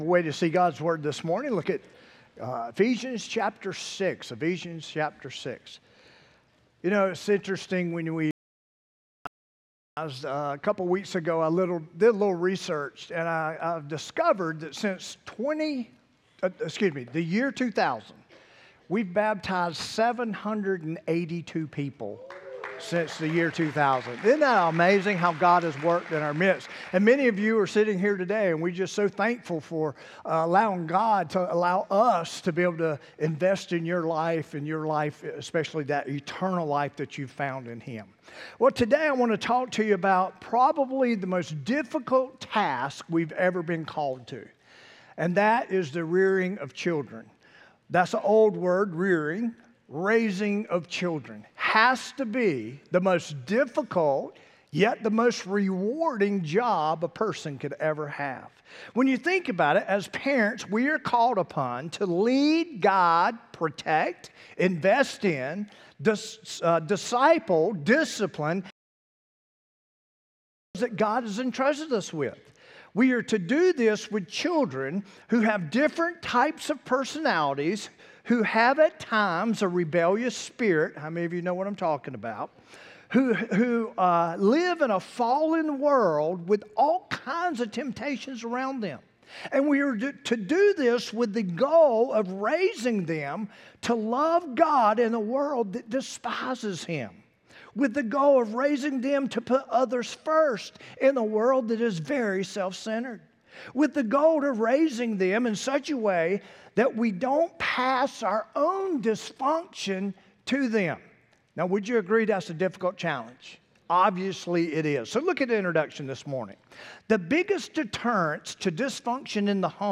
8-10-25-sermon-audio.m4a